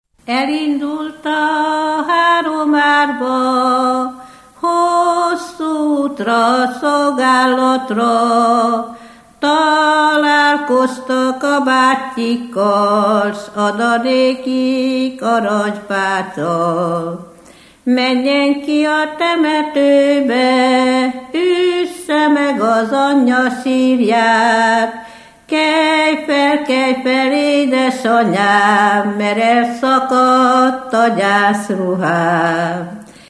Erdély - Kolozs vm. - Váralmás
Műfaj: Ballada
Stílus: 4. Sirató stílusú dallamok
Szótagszám: 8.8.8.8
Kadencia: 5 (4) 1 1